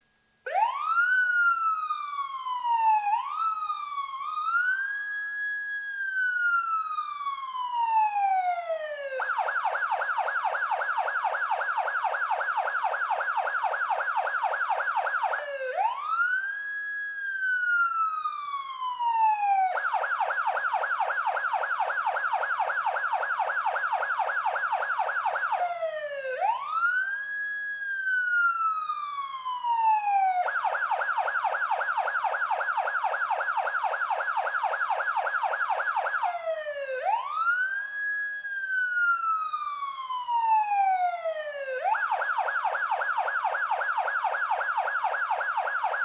Police Vehicle Siren